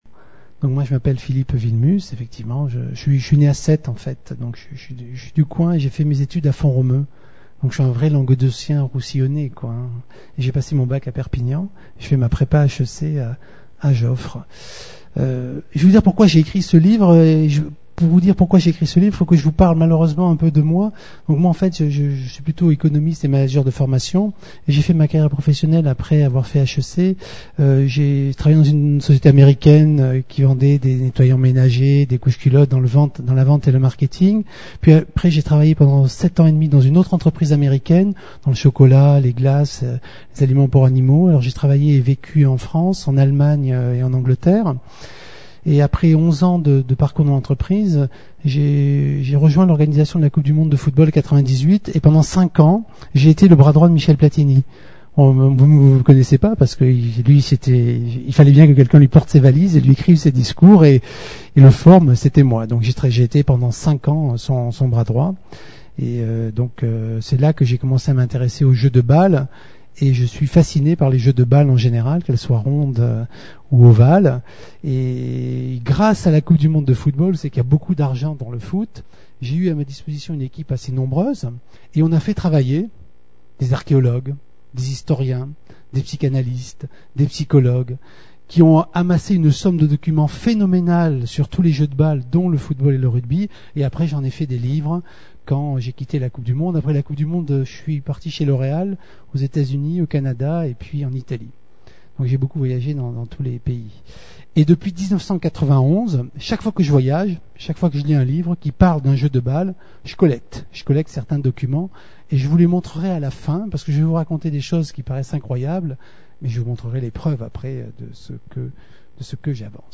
Rencontre littéraire